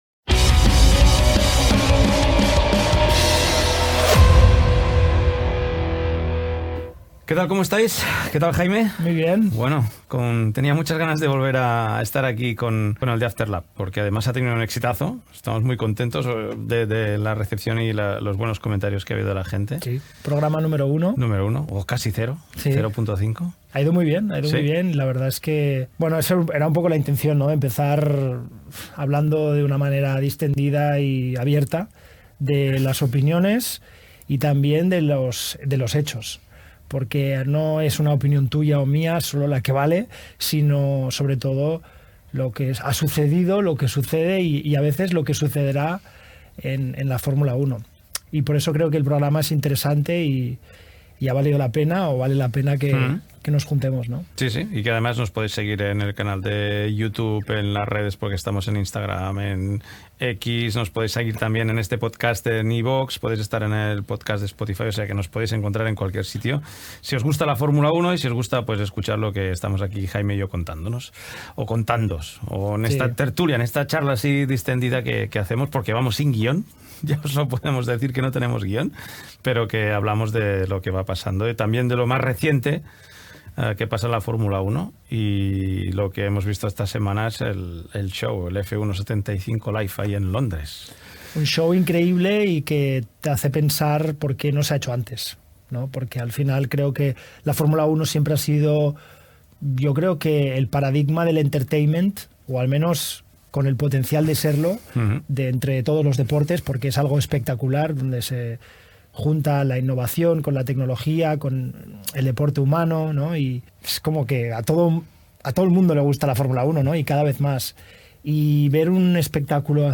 Tertúlia sobre l'actualitat de la Fórmula 1: la gala F1 75 Live celebrada a l'O2 Arena de Londres, la pel·lícula sobre la Fórmula 1, la xiulada al pilot Max Versappen de l'equip Red Bull